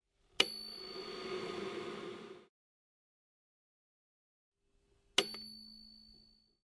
手榴弹的声音效果
描述：一扔，然后是叮叮当当，叮叮当当，然后是"嘭"。
标签： 手榴弹 SAS 爆炸 俄语 GIGN SWAT 名手 军队 二战 美国 WW1 碰杯 碰杯
声道立体声